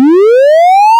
- Add voice siren audio files 2024-08-11 20:41:39 -06:00 86 KiB Raw History
BurgSiren.wav